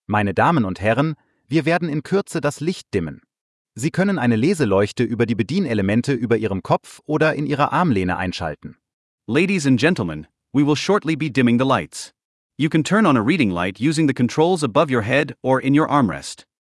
CabinDimTakeoff.ogg